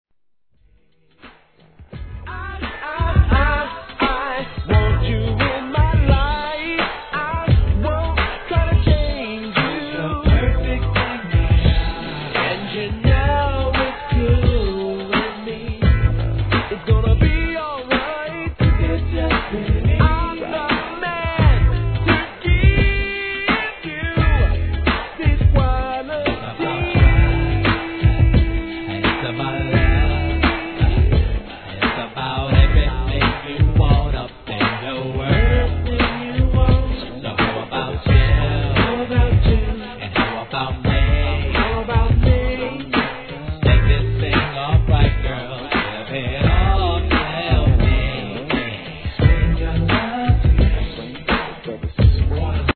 G-RAP/WEST COAST/SOUTH
ミディアムなメロ〜トラックにコーラスとRAPの絶妙なコンビネーション、マイナーG!!